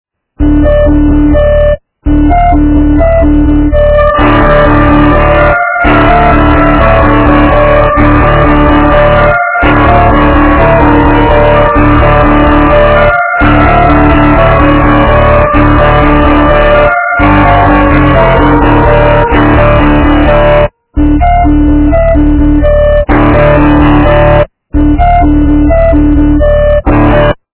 - рок, металл